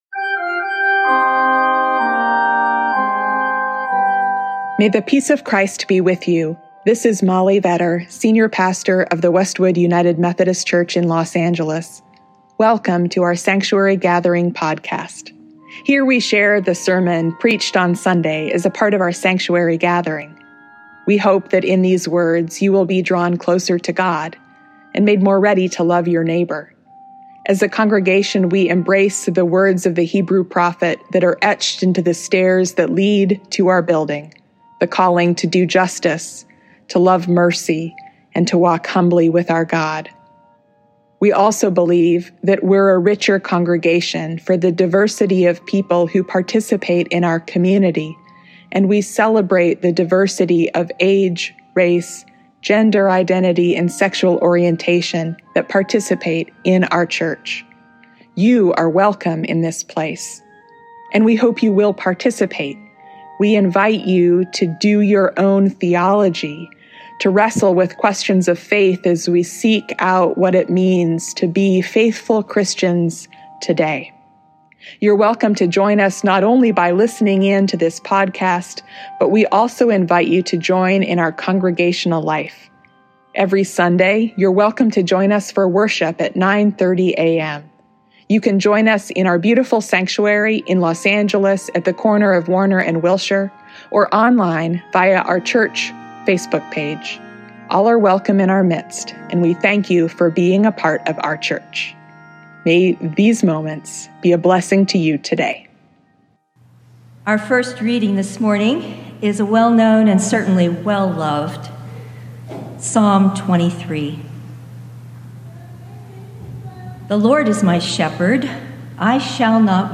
Theology depends on metaphors–on images and words that help us understand our God who is beyond all words. This Sunday, we start the first of three sermons that dive into familiar metaphors of God, exploring their rich possibility and their limits.